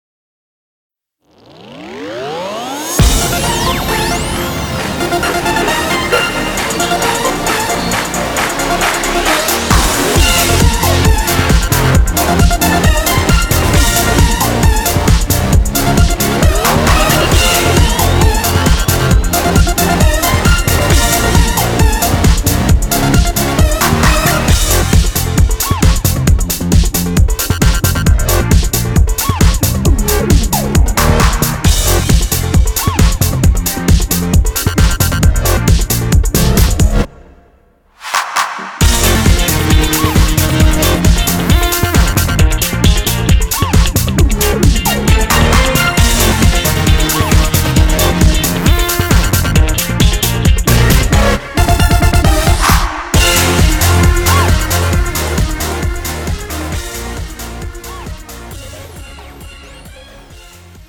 음정 (-1키)
장르 가요 구분 Premium MR
Premium MR은 프로 무대, 웨딩, 이벤트에 최적화된 고급 반주입니다.